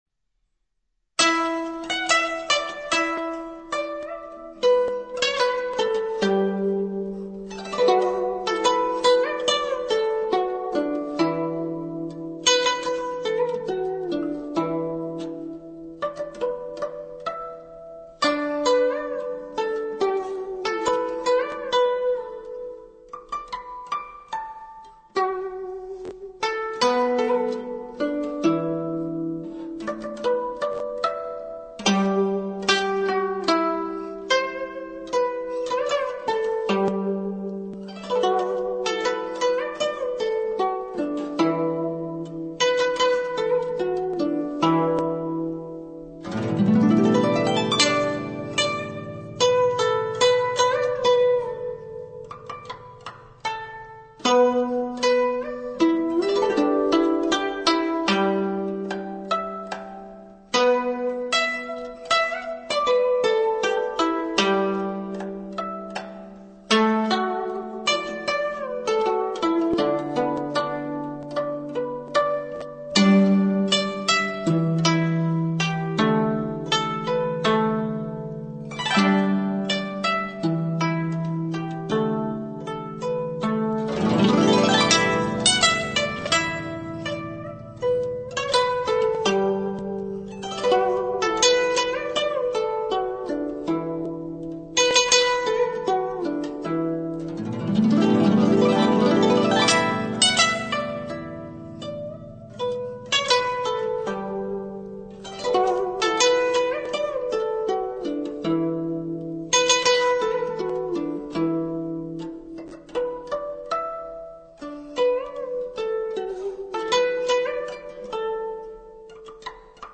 经过精心改编成古筝独奏曲